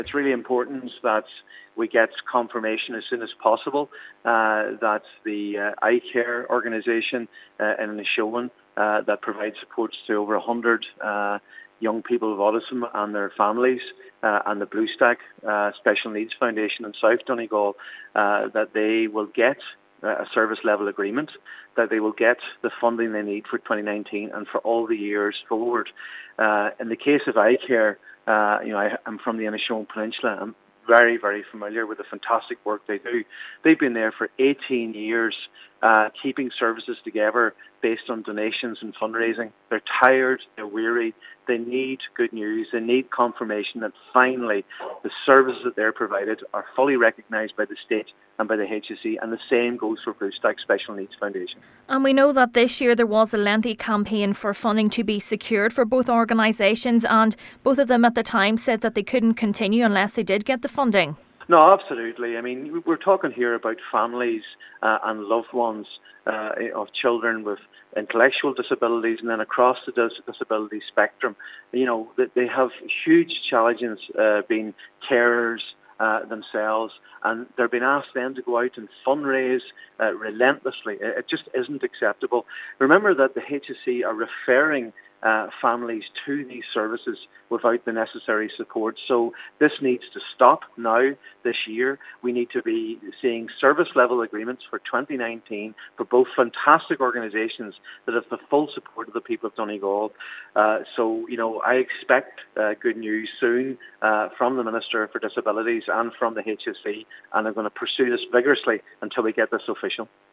Senator MacLochlainn says its imperative a commitment is given by the Government to support both organisations: